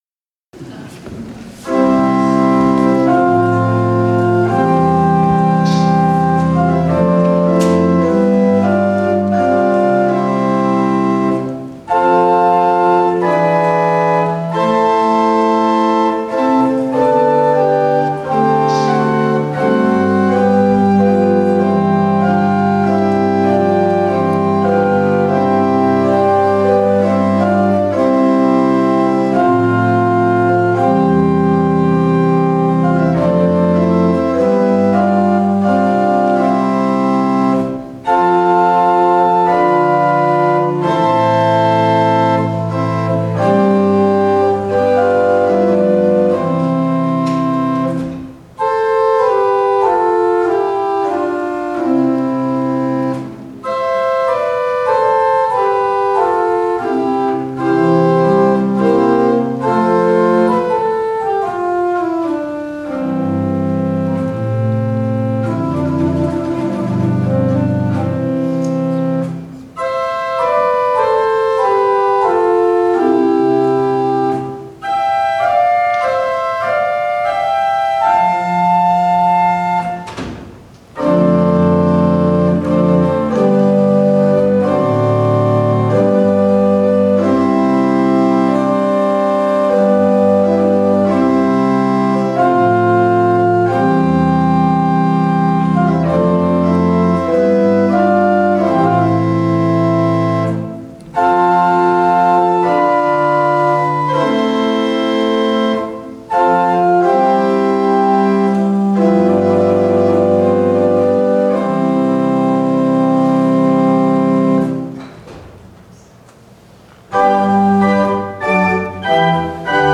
organist
1_lmcm_oct-2019_organ-solo-eve.mp3